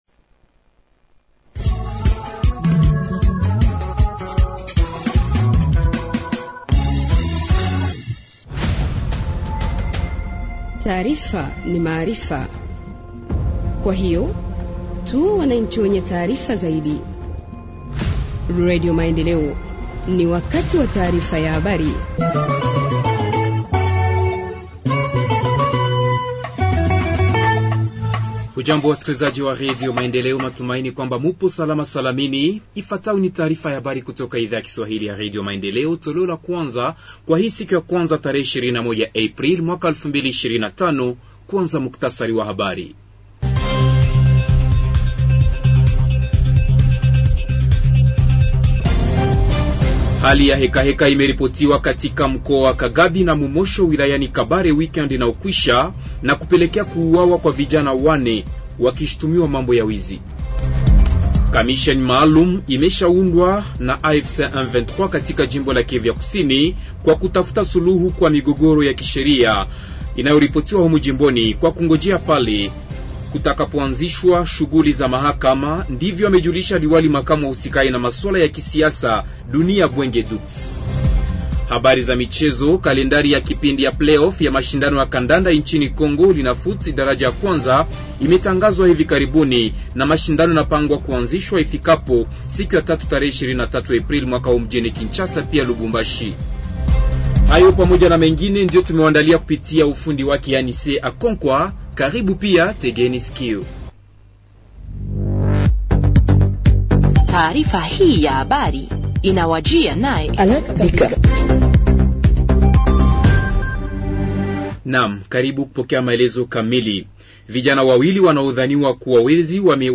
Journal en Swahili du 21 avril 2025 – Radio Maendeleo